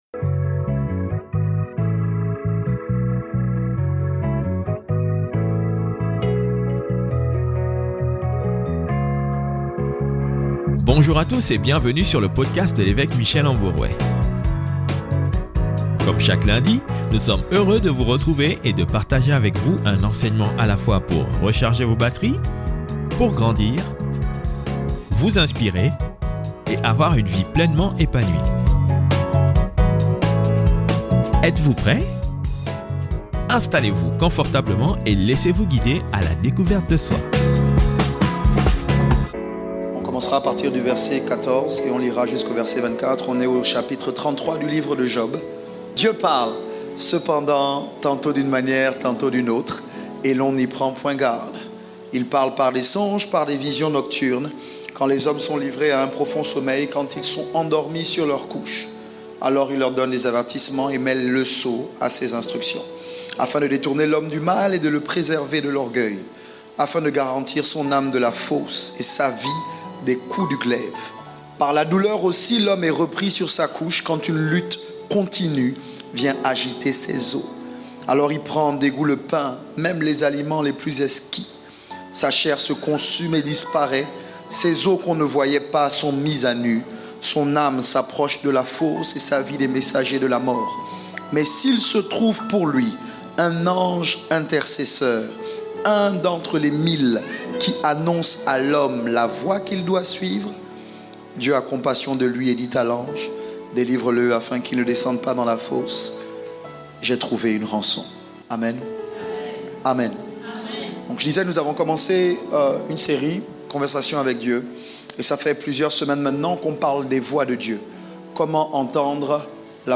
La douleur n'est pas toujours la conséquence d'une désobéissance à la voix de Dieu. Cet enseignement va aider quelqu'un.